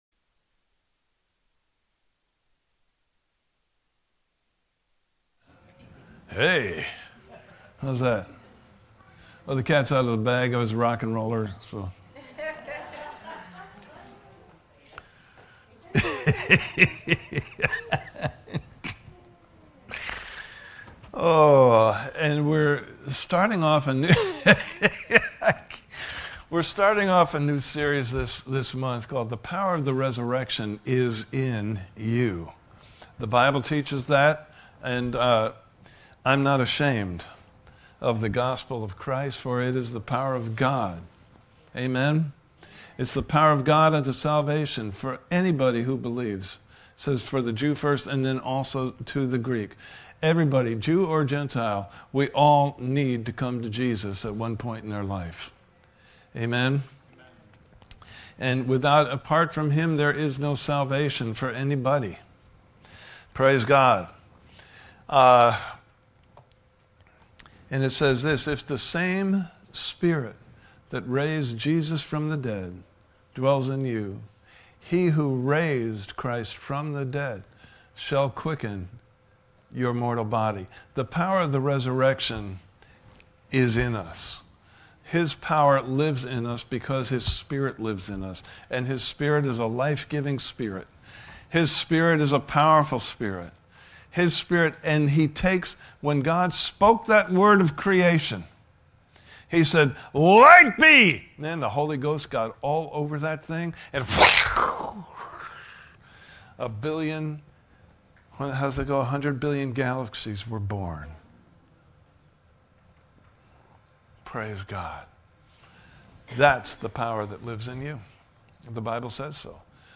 Service Type: Sunday Morning Service « The Power of Hope (Video) Series Overview – The Power Of The Resurrection Is IN YOU!